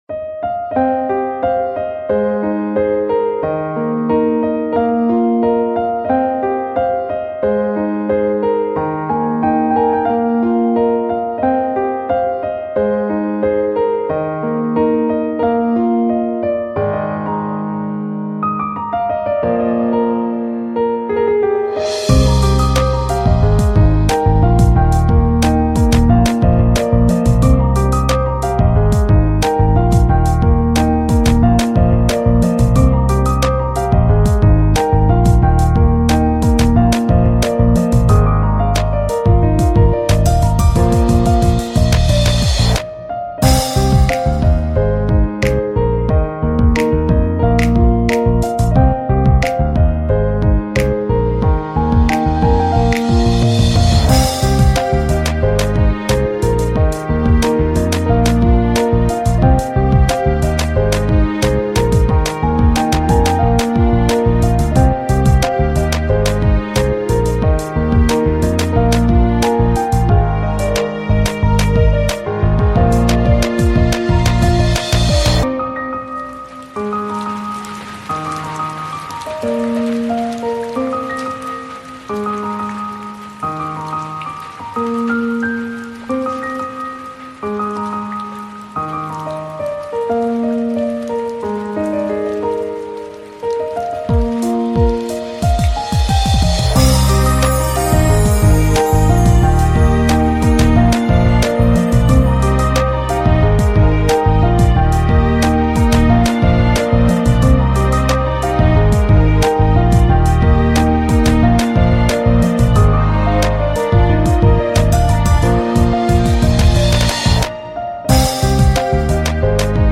背景音乐为轻松愉快的动画背景音配乐
该BGM音质清晰、流畅，源文件无声音水印干扰